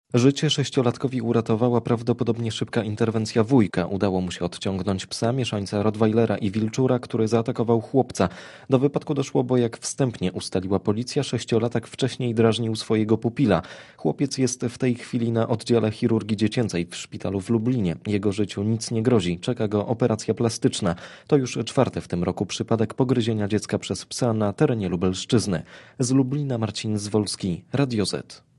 Posłuchaj korespondencji reportera Radia Zet (223 KB)